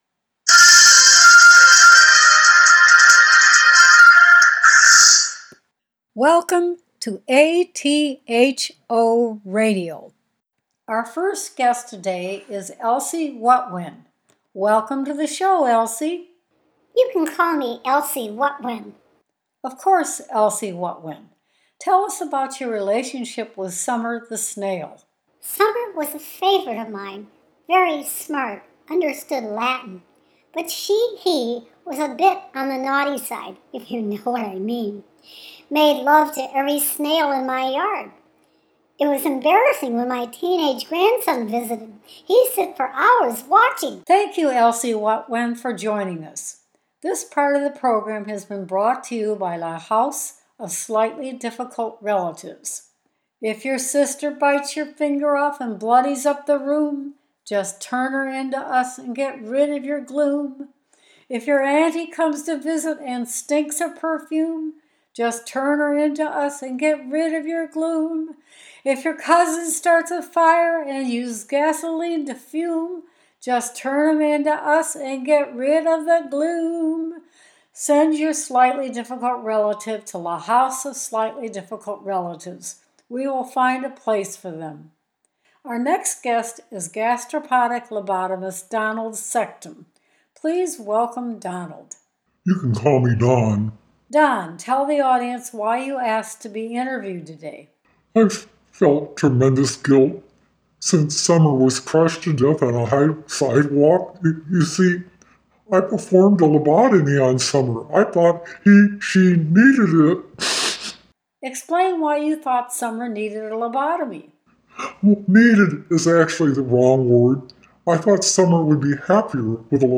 ATHOL RADIO BROADCAST
atho-radio-show-summer-the-snail.wav